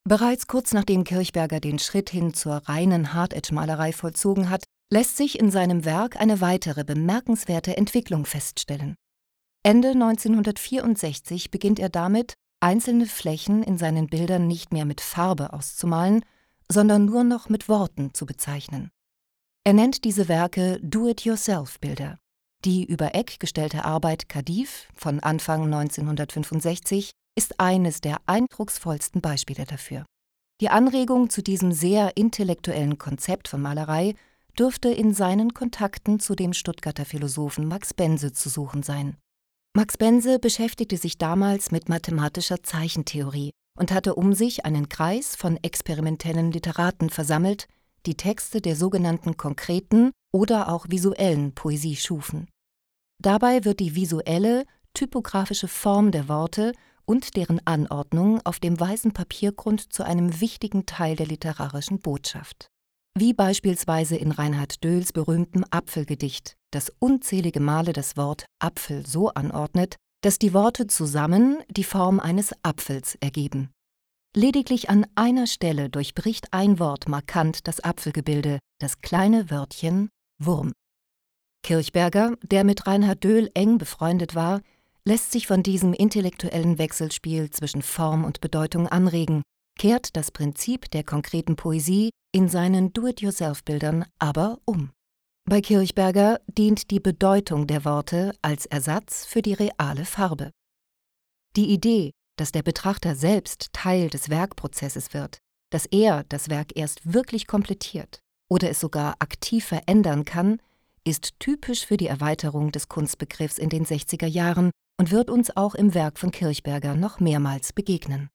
Ein Audioguide begleitet mit zusätzlichen Informationen an Hand von wichtigen Schlüsselwerken.